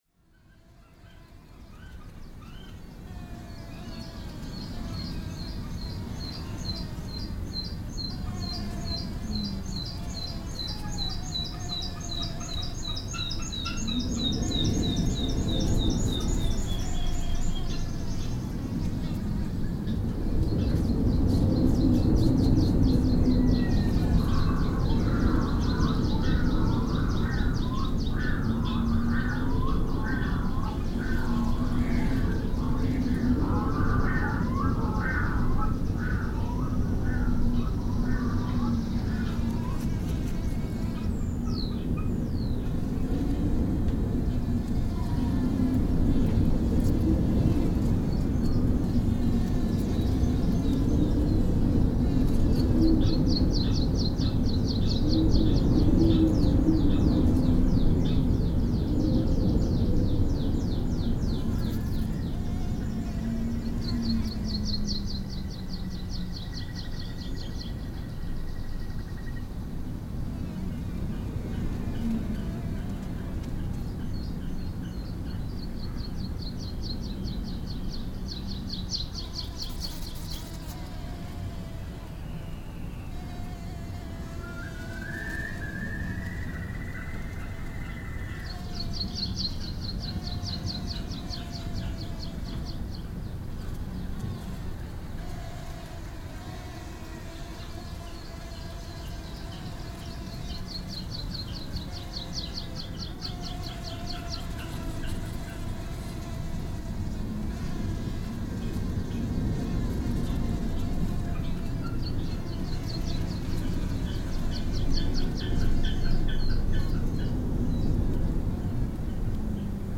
Posted in Náttúra, tagged Birds, Friðland í Flóa, Friðland í Flóa 2012, Nature reserve, Rode NT1a, Sound Devices 744, Vindur, Wind on 7.8.2012| 7 Comments »
As usual at summer solstance I recorded birds and ambiance in the nature reserve in Flói south of Iceland .
But inside the shelter was a window covered with hundreds of humming gnats.
Following recordings was made between midnight and half past two at 25th of June.
Mostly gusts and gnats. Birds are barely audible.
Wind has go down and the birds have start to sing. N.b. This is indoor recording so all birds sounds strange in this session.